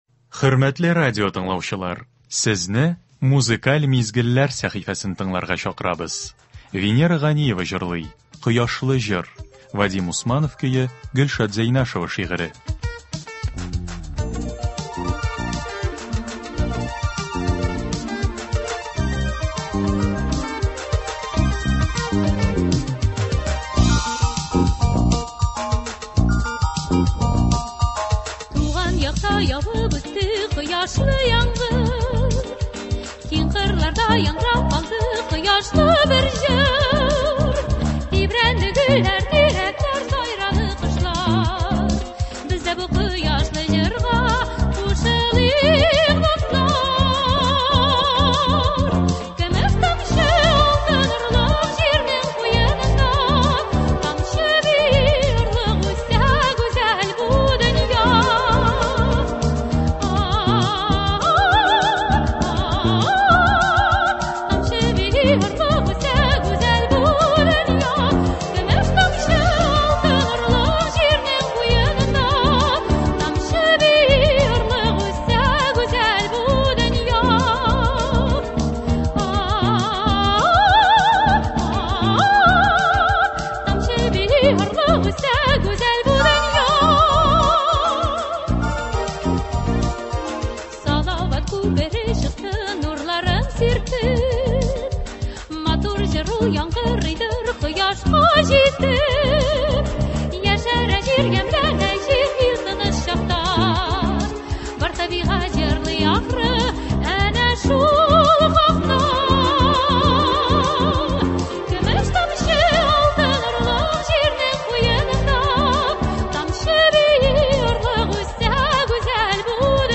Без сезнең өчен, хөрмәтле радиотыңлаучыларыбыз, яхшы кәеф, күңел күтәренкелеге бирә торган концертларыбызны дәвам итәбез.